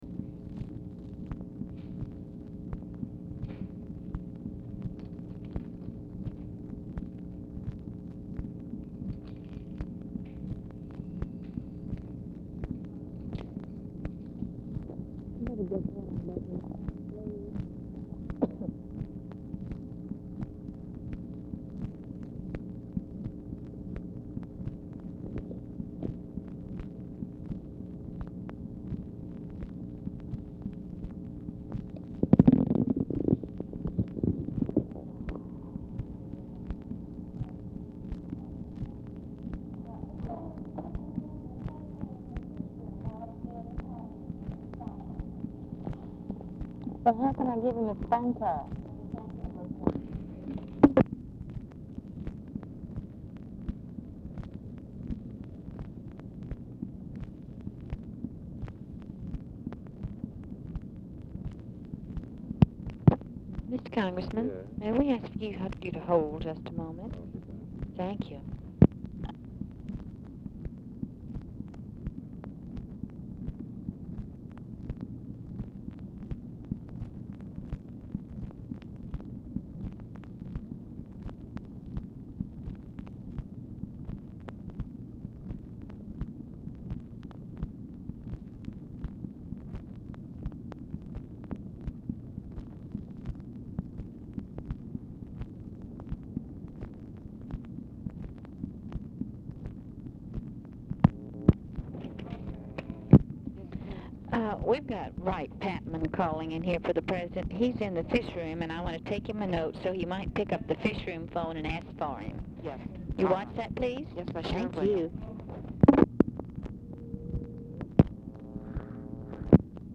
Telephone conversation # 8811, sound recording, LBJ and WRIGHT PATMAN, 9/1/1965, 5:45PM | Discover LBJ
OFFICE CONVERSATION BETWEEN OFFICE SECRETARIES PRECEDES CALL; PATMAN ON HOLD 2:00
Format Dictation belt
Specific Item Type Telephone conversation